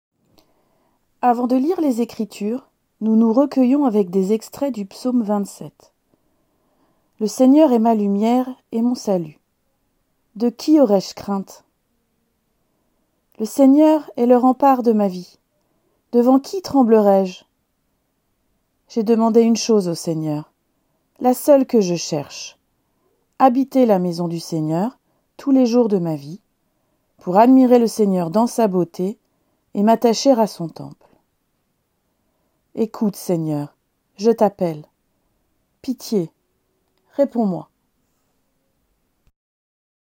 CULTE DU 14 MARS 2021 "la vie éternelle en nous"